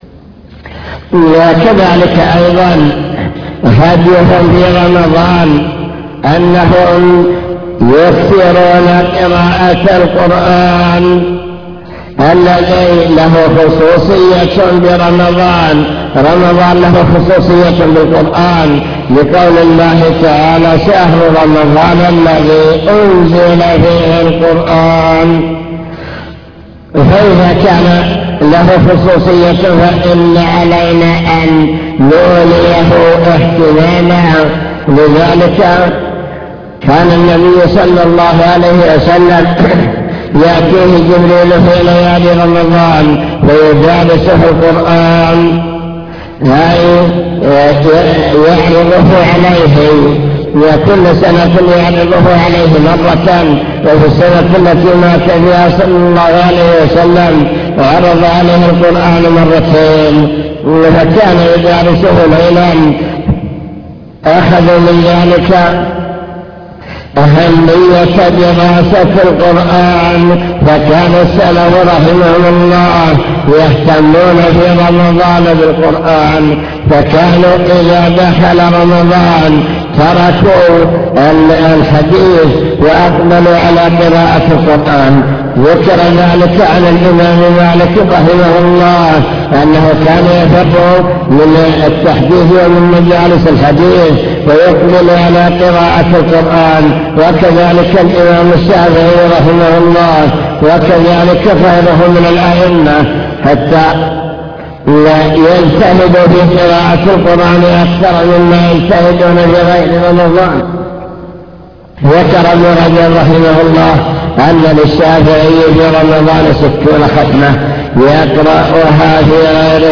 المكتبة الصوتية  تسجيلات - محاضرات ودروس  مجموعة محاضرات ودروس عن رمضان هدي السلف الصالح في رمضان